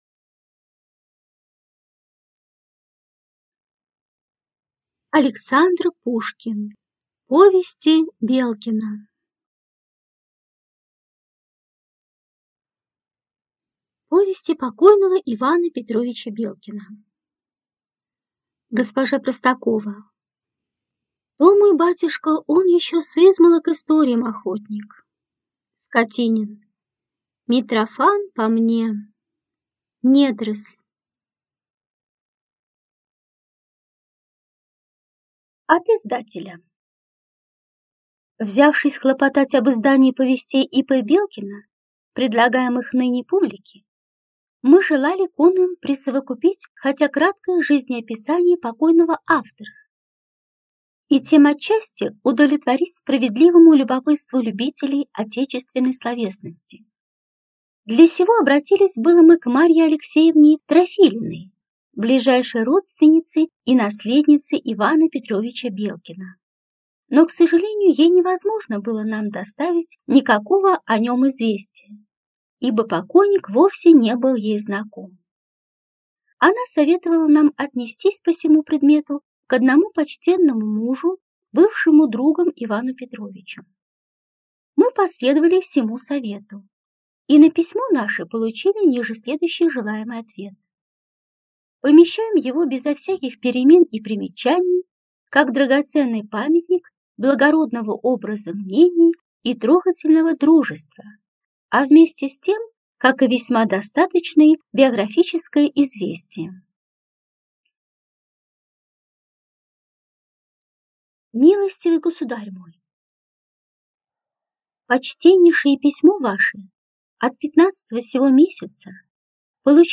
Аудиокнига Повести Белкина | Библиотека аудиокниг